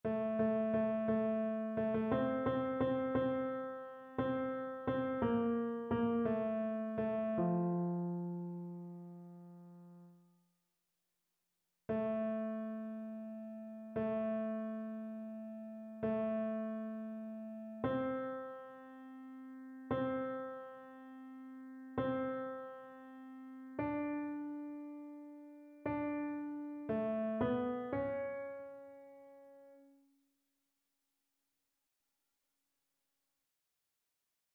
Chœur
annee-a-temps-ordinaire-11e-dimanche-psaume-99-tenor.mp3